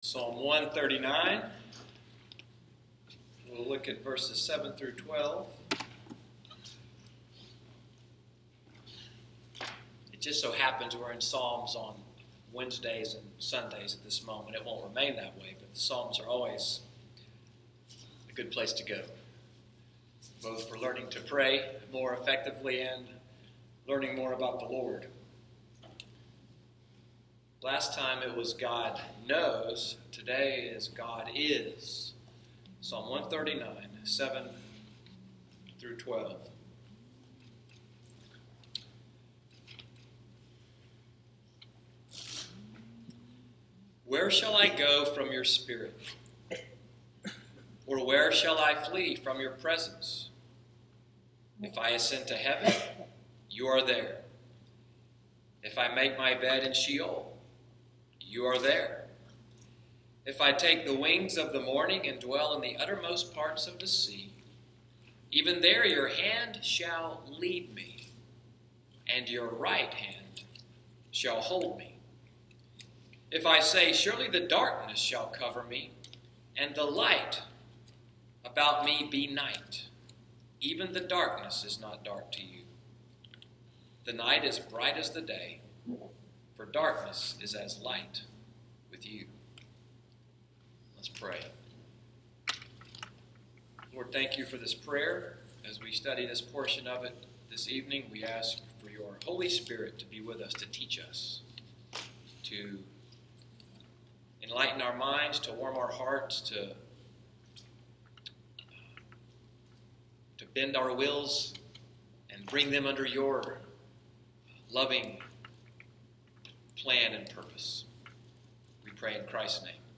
Wednesday Evening Bible Study at NCPC, “Psalm 139:7-12 Bible study,” August 3, 2016.